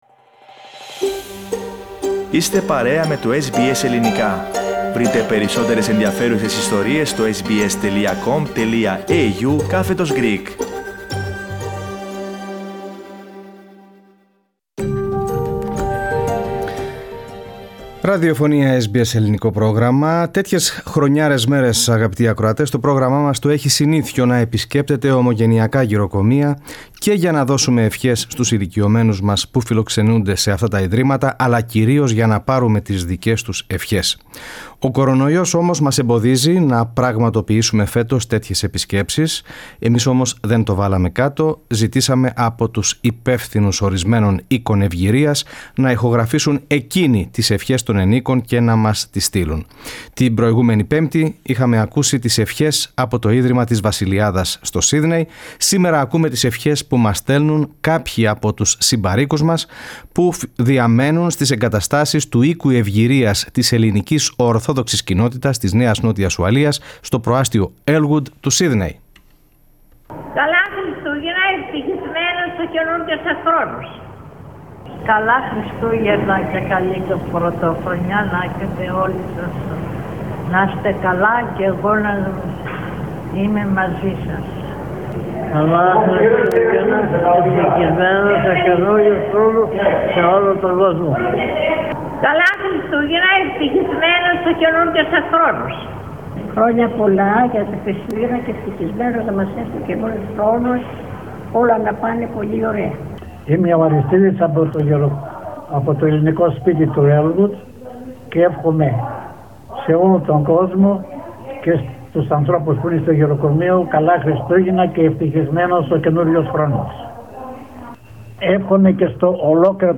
Οι φιλοξενούμενοι στο «Ελληνικό Σπίτι» της Κοινότητας στο Earlwood μας εύχονται χρόνια πολλά!
Εμείς όμως δεν το βάλαμε κάτω και ζητήσαμε από τους υπεύθυνους ορισμένων Οίκων Ευγηρίας να ηχογραφήσουν εκείνοι τις ευχές των ενοίκων.